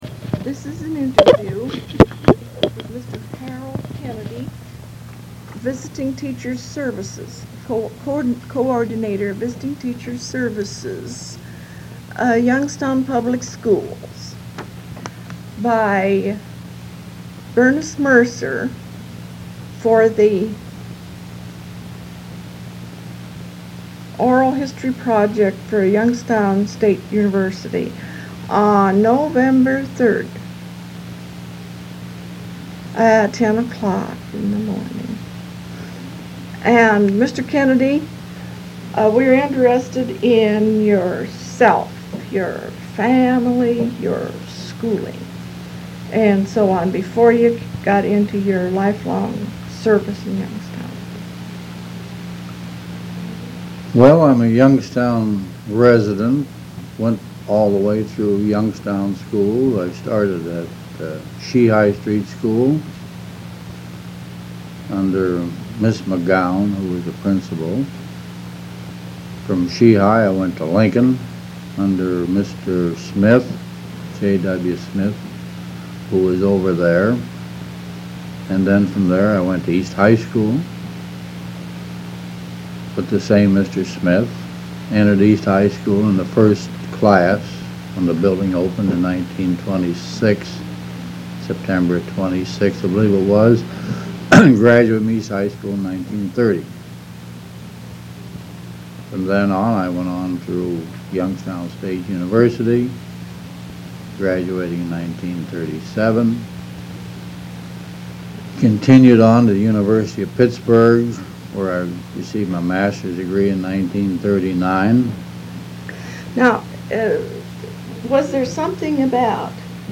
Transcript of interview taped on November 3, 1975.
Oral Histories